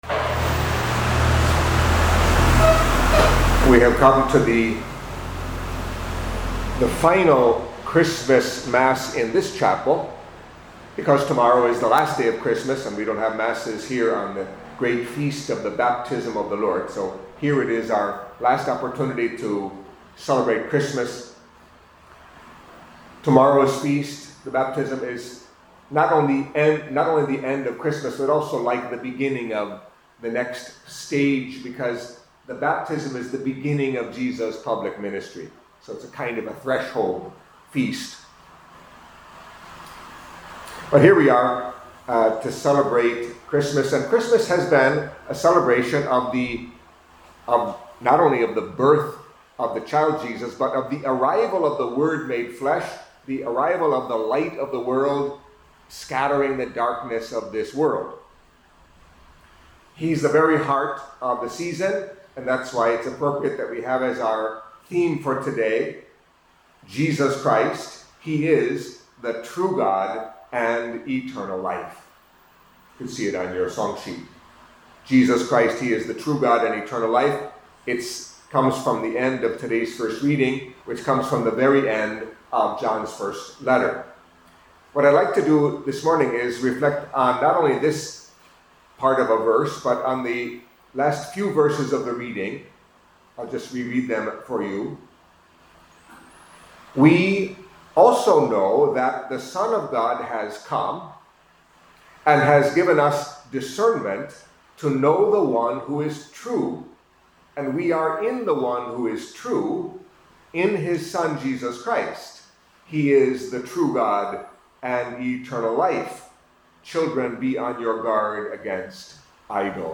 Catholic Mass homily for Saturday after Epiphany